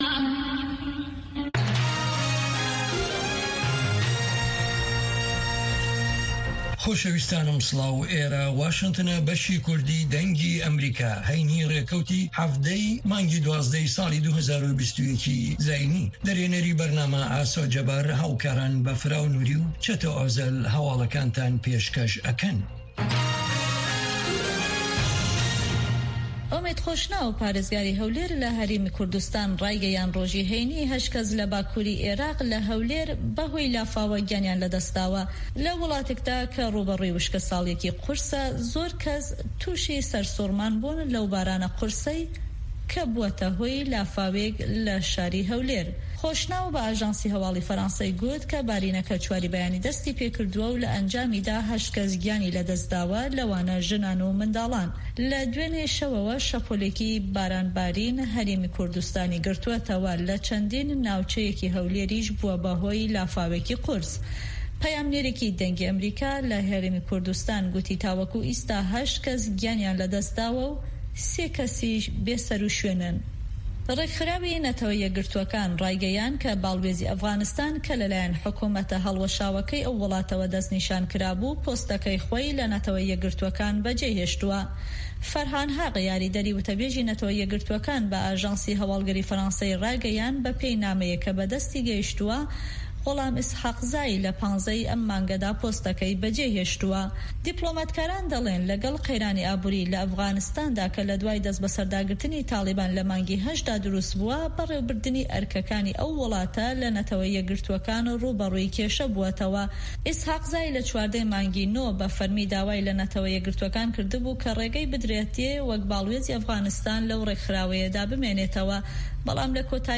هه‌واڵه‌کان، ڕاپۆرت ، وتووێژ